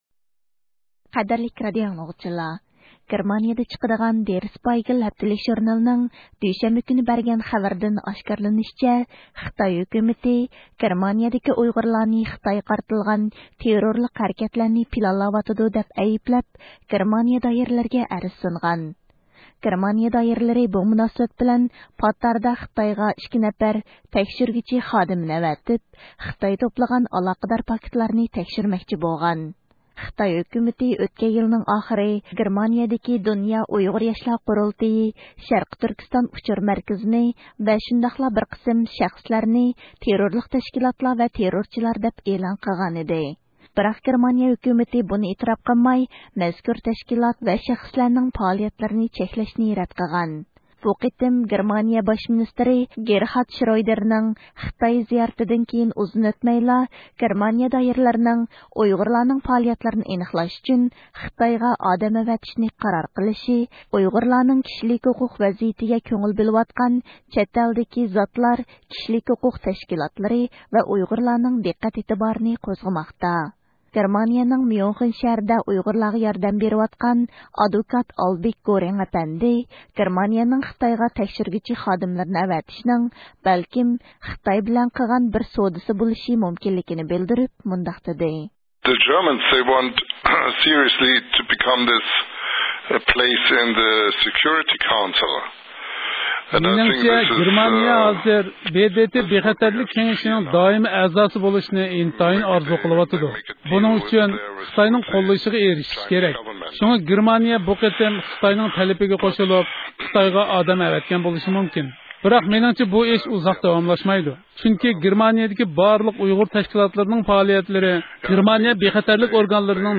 «تېررورچى» لار تىزىملىكىدىكى دولقۇن ئەيسا ئەپەندى بىلەن سۆھبەت
مۇخبىرىمىزنىڭ بۇ ھەقتىكى زىيارىتىنى يۇقىرىدىكى ئاۋاز ئۇلىنىشىدىن ئاڭلاڭ